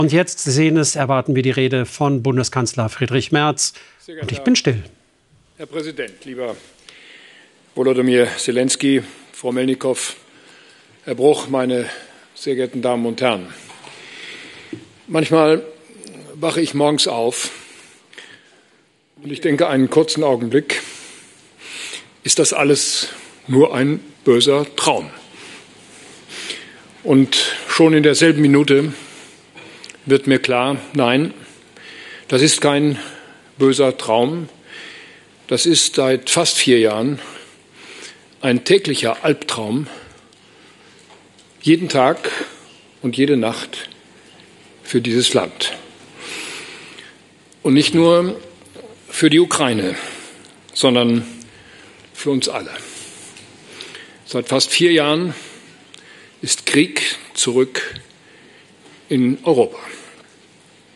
Sichtlich ergriffener Kanzler ringt mit den Tränen: "Ist das eigentlich alles nur ein böser Traum?"
Zum achten Mal kommt das deutsch-ukrainische Wirtschaftsforum
Als Friedrich Merz zu seiner Rede ansetzt, ist dem Bundeskanzler